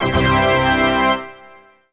LH fanfare (V2).wav